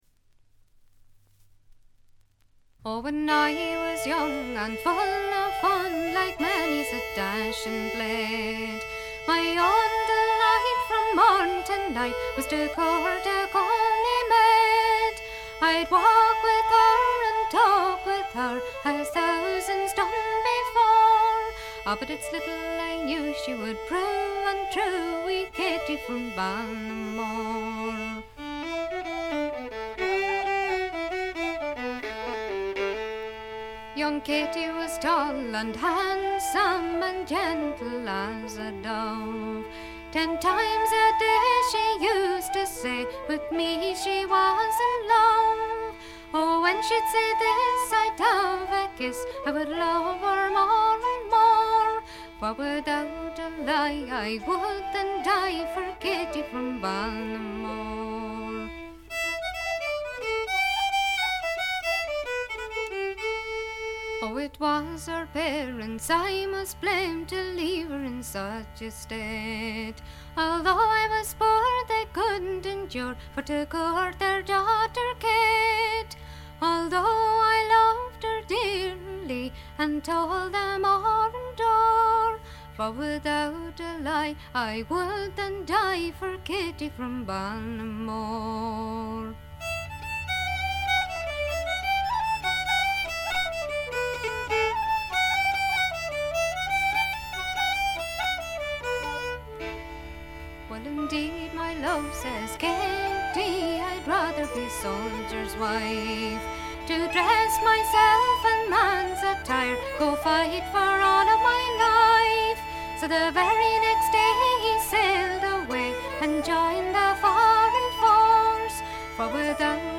ほとんどノイズ感無し。
時に可憐で可愛らしく、時に毅然とした厳しさを見せる表情豊かで味わい深いヴォーカルがまず最高です。
試聴曲は現品からの取り込み音源です。